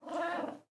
Minecraft Version Minecraft Version latest Latest Release | Latest Snapshot latest / assets / minecraft / sounds / mob / cat / ocelot / idle2.ogg Compare With Compare With Latest Release | Latest Snapshot